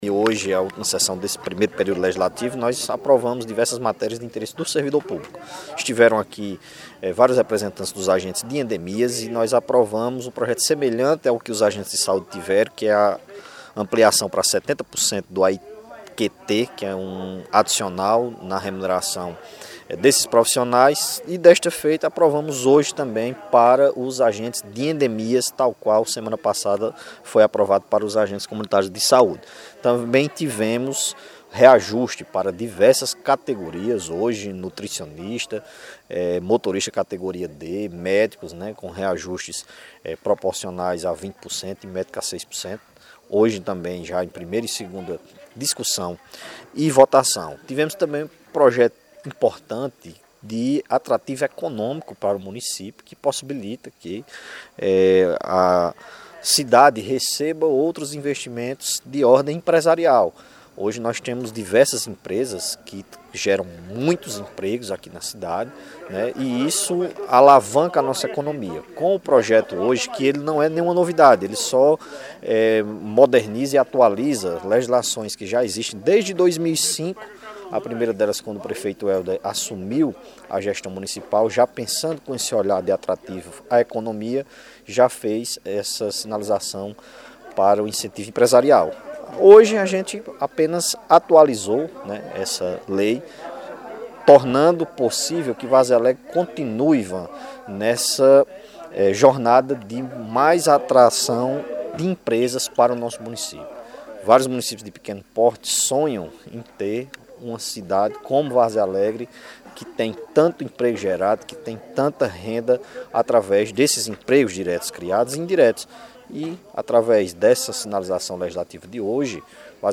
O vereador líder do governo na Câmara, Otoniel Fiuza Jr., comenta os projetos avaliados.